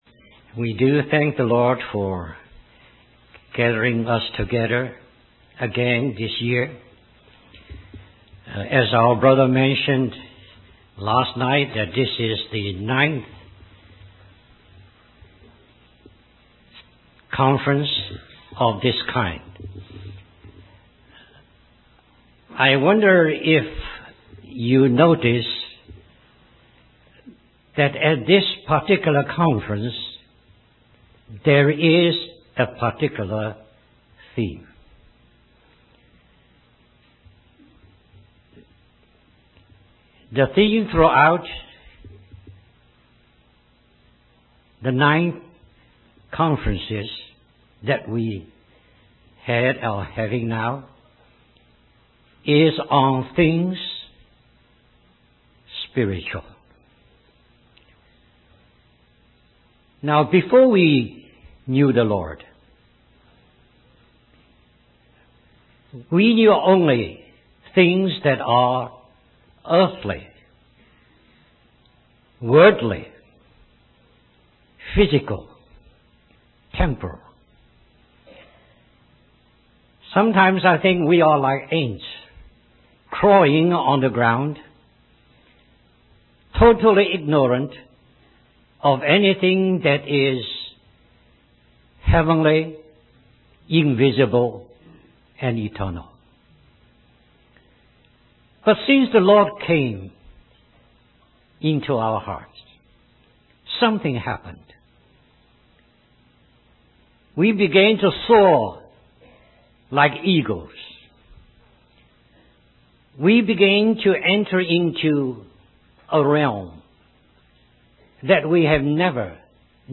In this sermon, the speaker emphasizes the importance of seeking the Lord and having spiritual knowledge. They compare our previous state of ignorance to ants crawling on the ground, but since knowing the Lord, we have entered into a vast realm of the spiritual.
The theme of this conference is focused on spiritual matters, and the speaker encourages the audience to live in the heavenlies and seek spiritual knowledge.